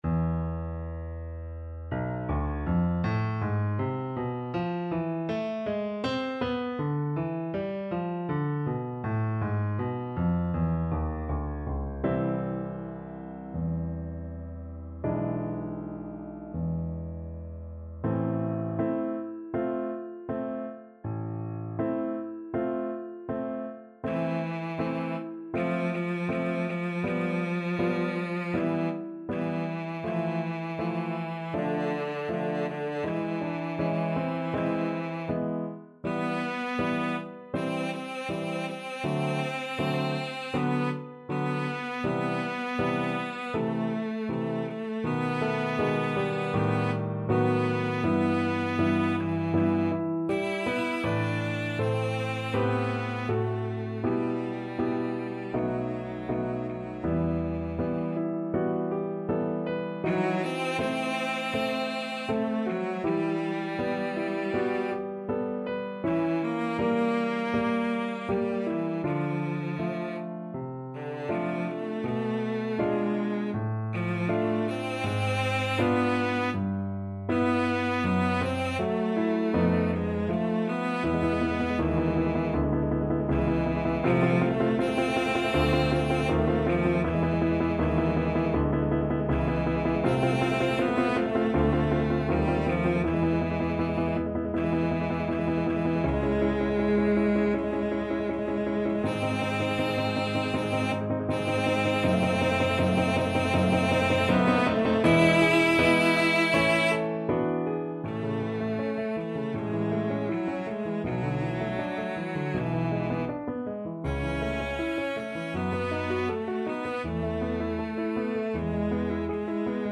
CelloPiano
~ = 100 Molto moderato =80
4/4 (View more 4/4 Music)
Cello  (View more Easy Cello Music)
Classical (View more Classical Cello Music)